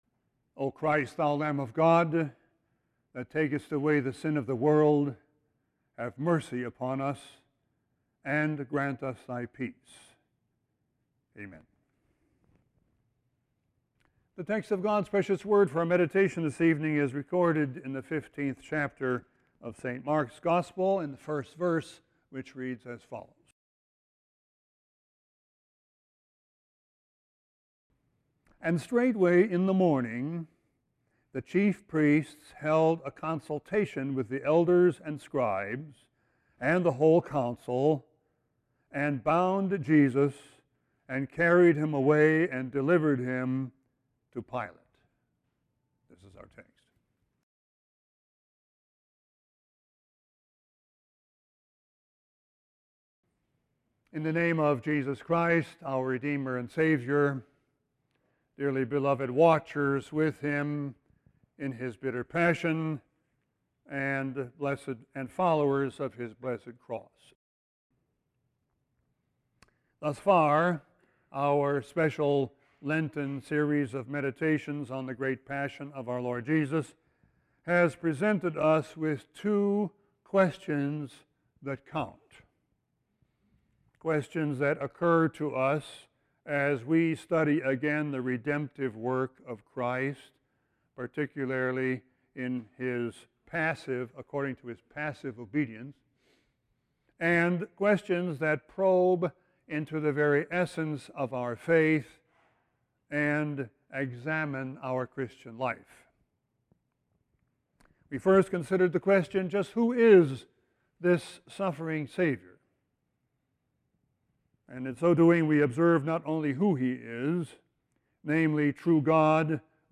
Sermon 2-24-16.mp3